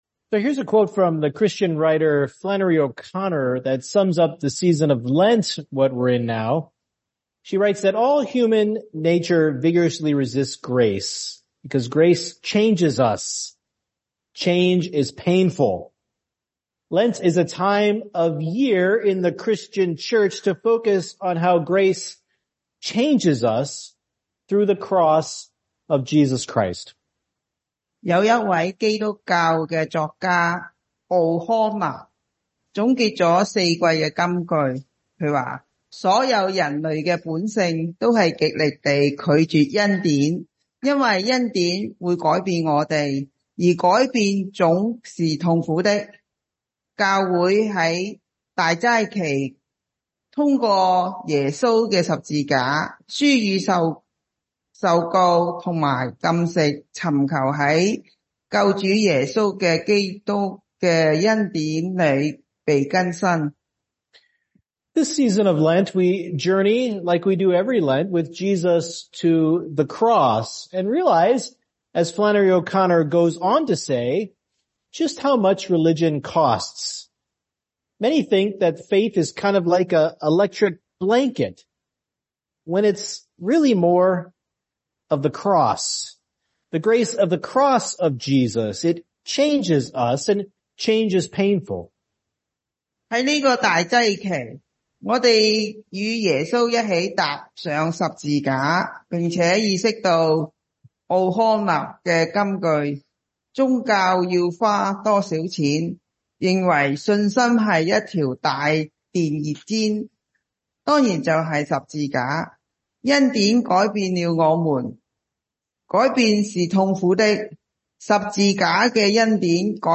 Chinese Service – Providing Sacrifice (Mark 1:9-15)